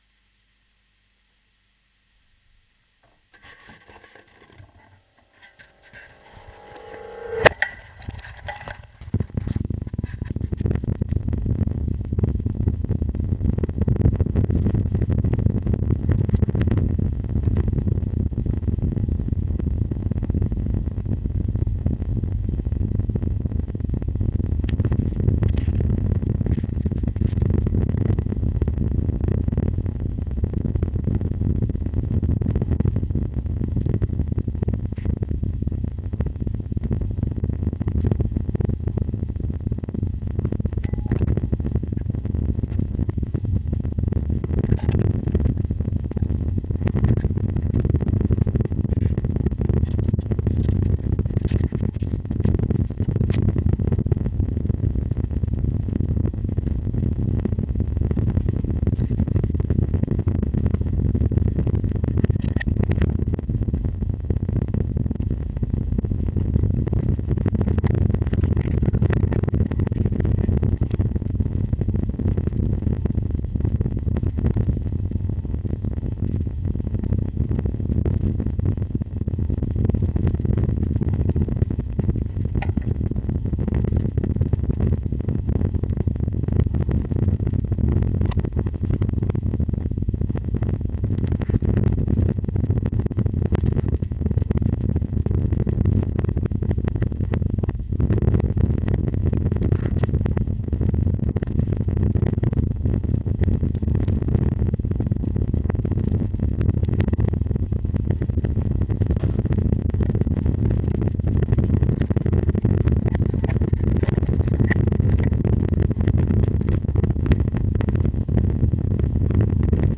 2016 Kontaktmikro Feedback (Video)
01 Take324-1-Kontaktmikro Feedback.flac (4:47)
Mix der Aufnahmen, vorwärts und rückwärts (digital in Audacity):